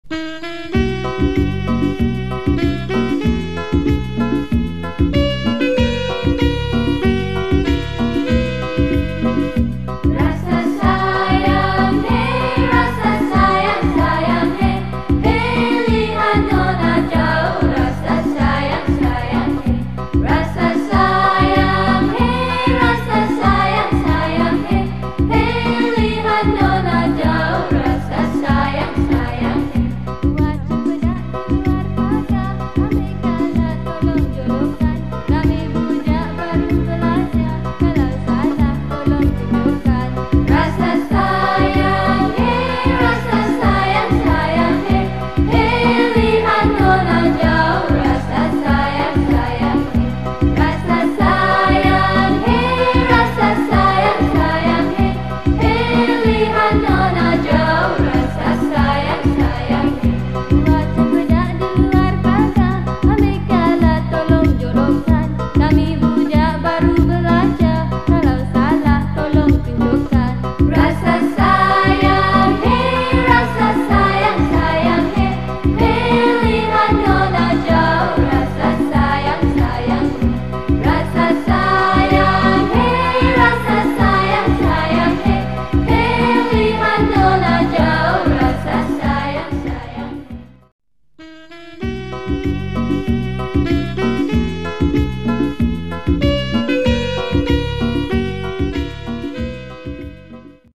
Children Song , Malay Song